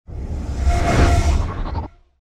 mixed-ghost-voices